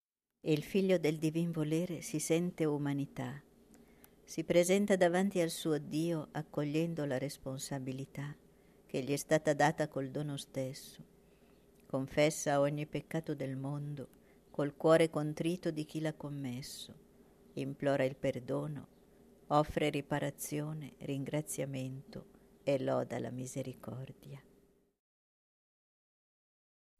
nr. 25 Preghiera mp3 Kyrie Eleison sono l’Umanità canto mp3